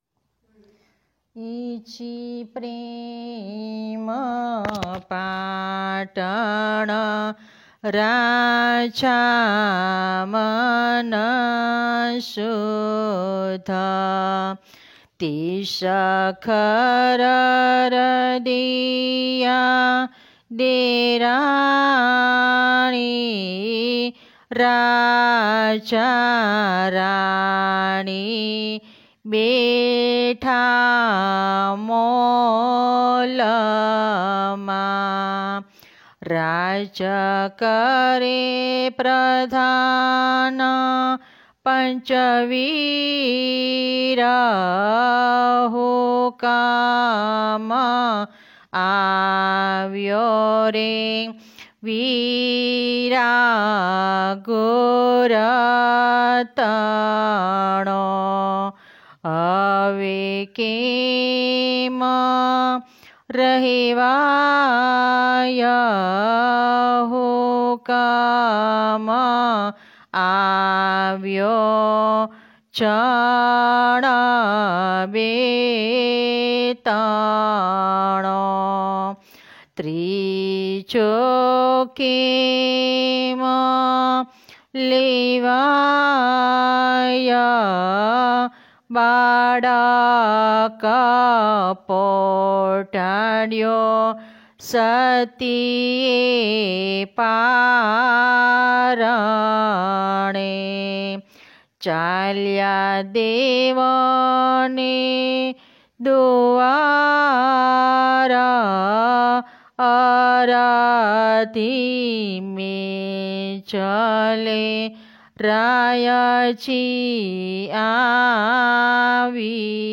Ginan – Prem Patan